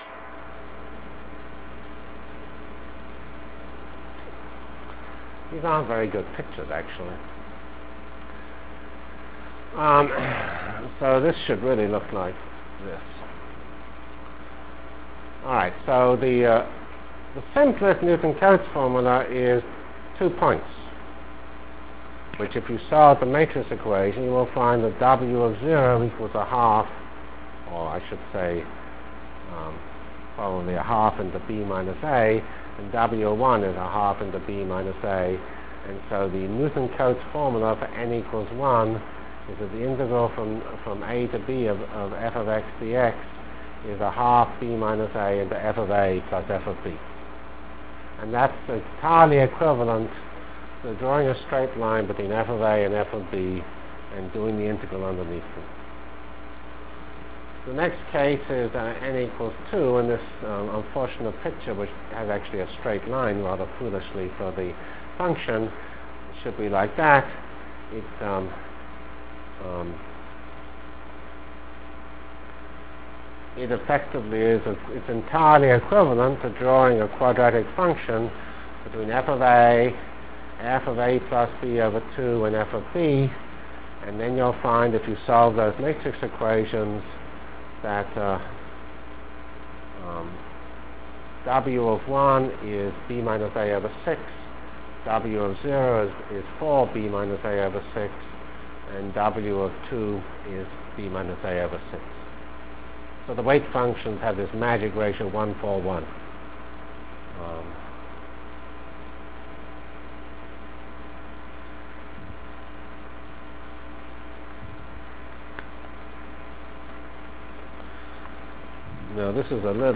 From CPS615-End of N-Body Discussion and Beginning of Numerical Integration Delivered Lectures of CPS615 Basic Simulation Track for Computational Science -- 15 October 96. *